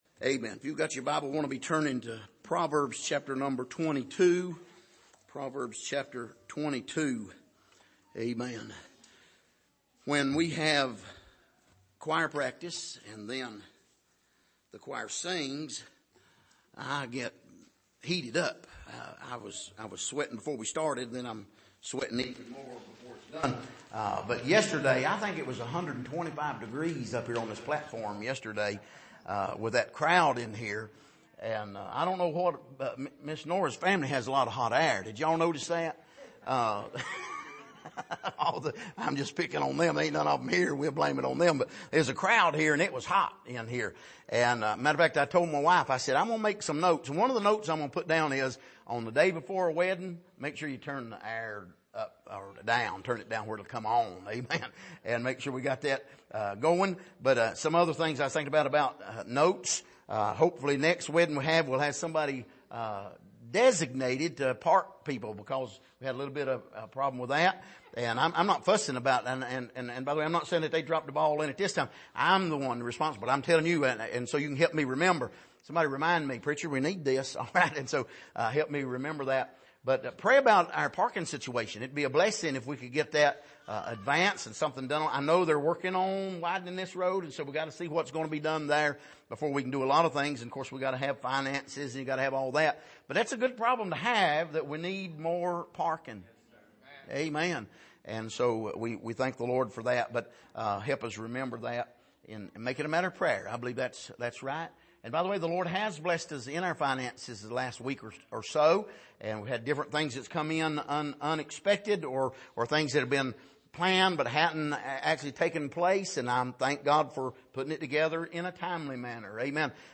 Passage: Proverbs 22:16-23 Service: Sunday Evening